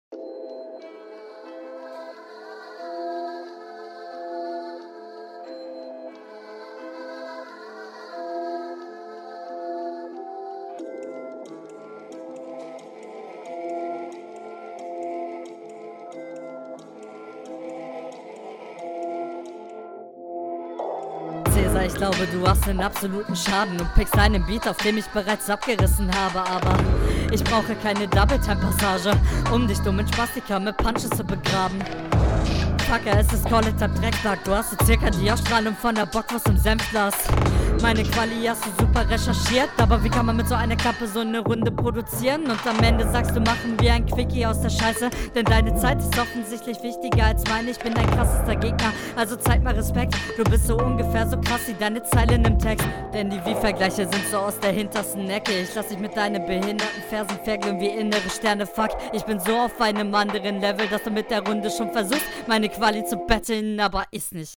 Hast du dich hochgepitcht oder Helium geatmet?